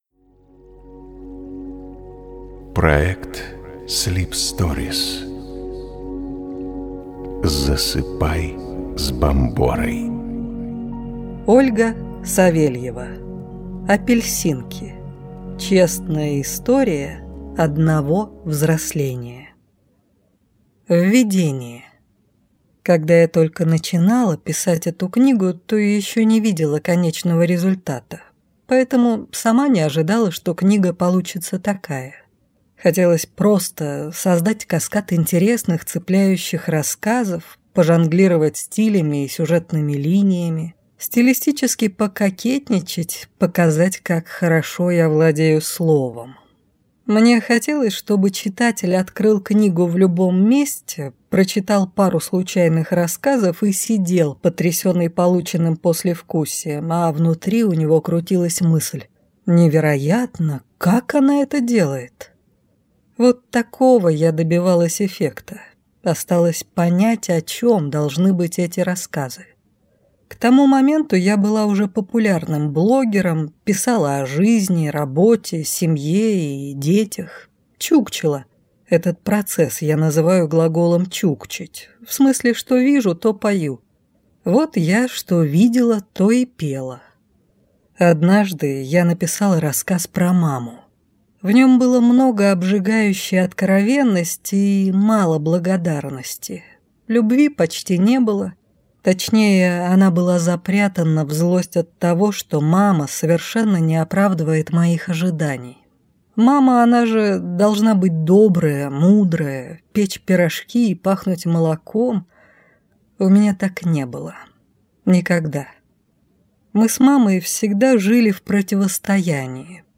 Аудиокнига Апельсинки. Честная история одного взросления | Библиотека аудиокниг
Прослушать и бесплатно скачать фрагмент аудиокниги